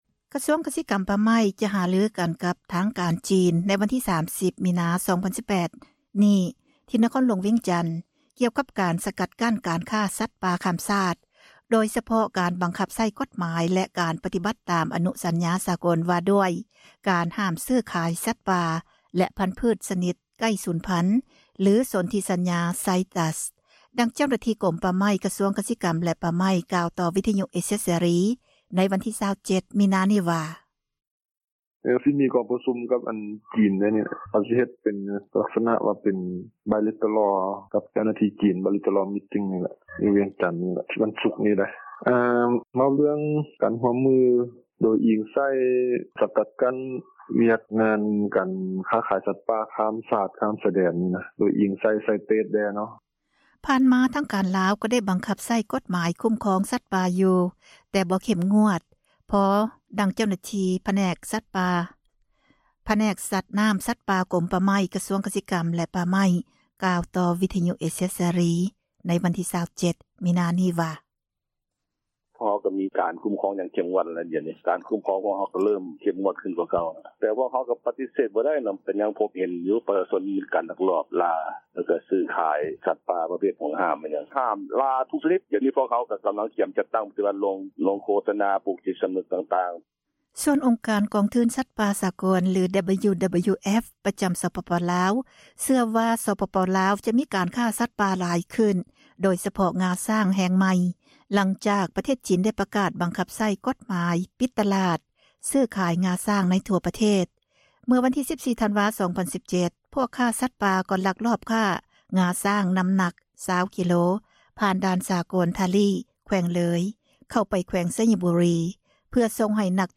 ດັ່ງເຈົ້າໜ້າທີ່ ກົມປ່າໄມ້ ກະຊວງກະສິກັມ ແລະປ່າໄມ້ ກ່າວຕໍ່ ວິທຍຸເອເຊັຽ ເສຣີ ໃນ ວັນທີ 27 ມິນາ ນີ້ວ່າ:
ຜ່ານມາທາງການລາວ ກໍໄດ້ບັງຄັບໃຊ້ກົດໝາຍ ຄຸ້ມຄອງສັດປ່າ ຢູ່ ແຕ່ບໍ່ເຂັ້ມງວດພໍ ດັ່ງເຈົ້າໜ້າທີ່ ຜແນກສັດນໍ້າ-ສັດປ່າ ກົມປ່າໄມ້ ກະຊວງກະສິກັມ ແລະປ່າໄມ້ ກ່າວຕໍ່ວິທຍຸ ເອເຊັຍເສຣີ ໃນວັນທີ່ 27 ມີນາ ນີ້ວ່າ: